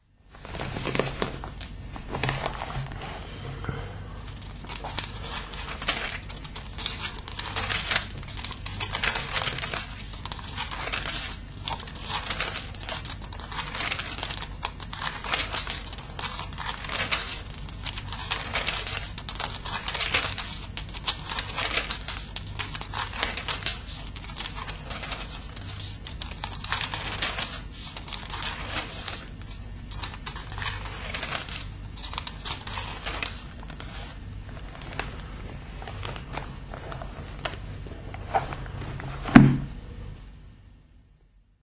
描述：翻开教会在1942年送给我父亲的《圣经》（荷兰语译本）中的《撒母耳记上》的书页。